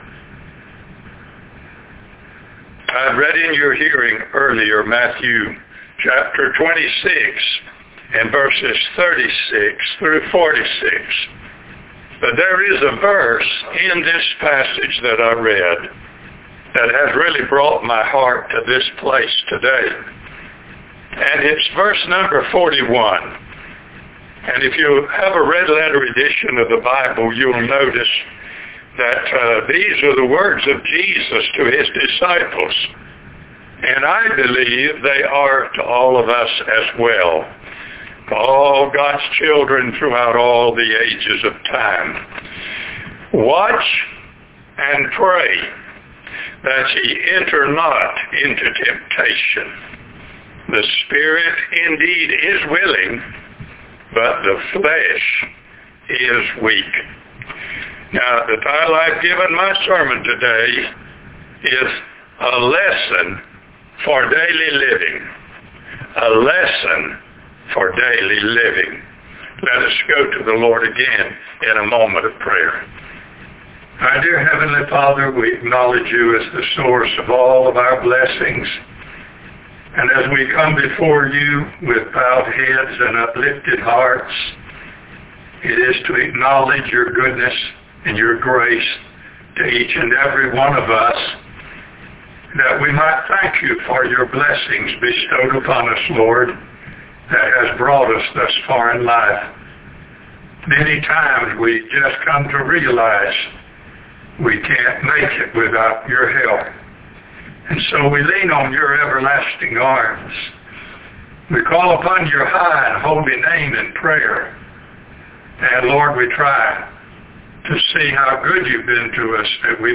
Sermons Previously Used